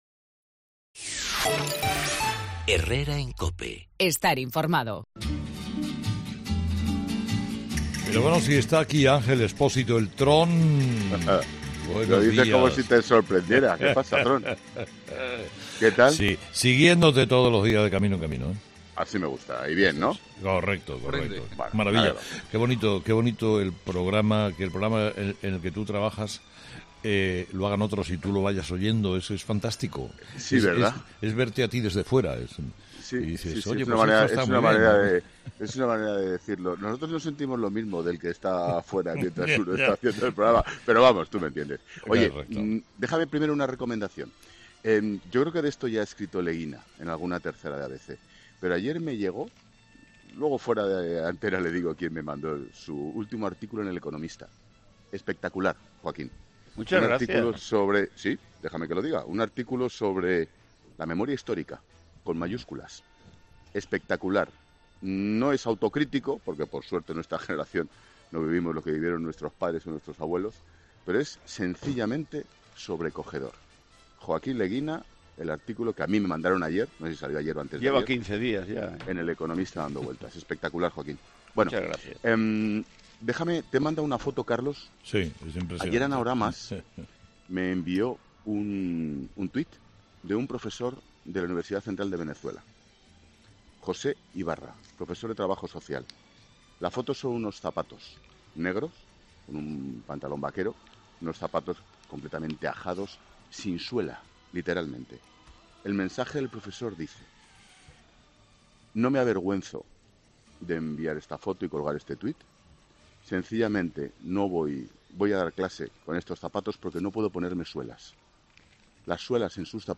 Escucha ahora el 'Paseíllo del Tron', emitido este 4 de julio de 2018, en ‘Herrera en COPE’